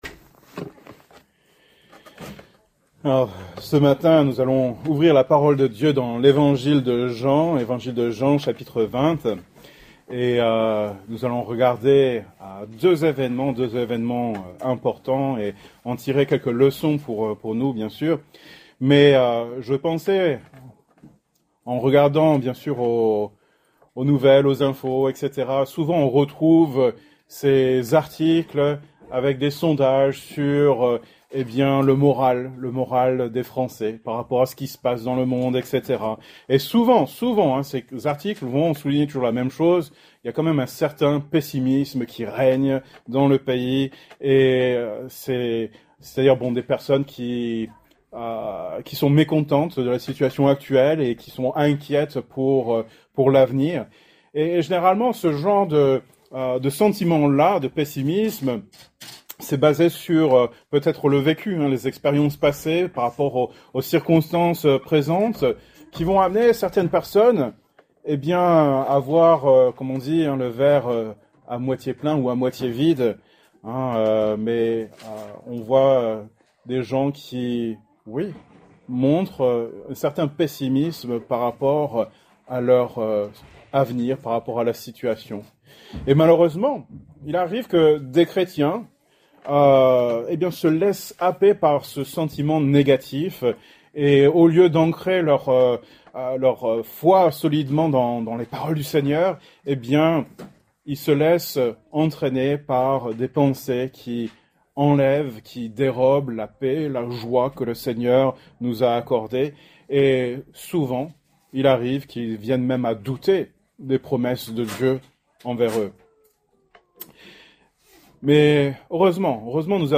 Thème: Doute , Foi , Pâques Genre: Prédication